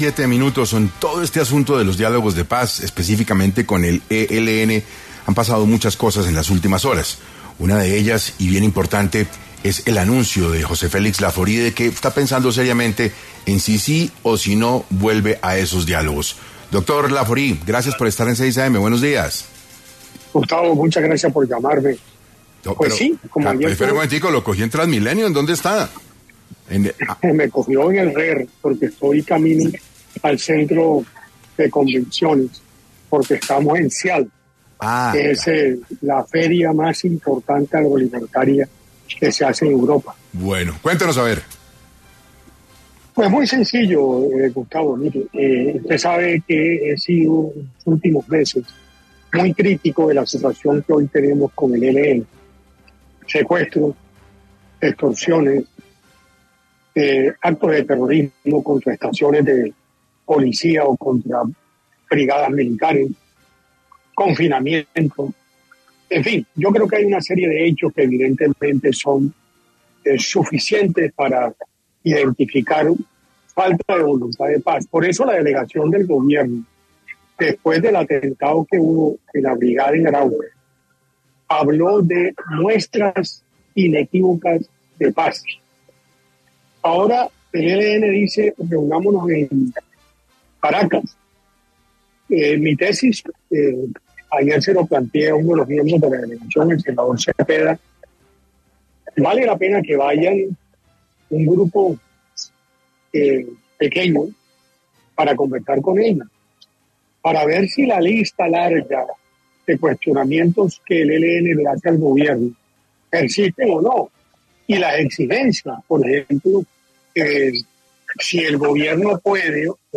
José Félix Lafaurie estuvo en entrevista con la mesa de trabajo de 6AM, luego de que anunciara que estaba reconsiderando mantenerse como negociador con el Gobierno y el ELN.